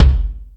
Lotsa Kicks(24).wav